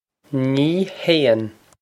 Pronunciation for how to say
Nee hayn
This is an approximate phonetic pronunciation of the phrase.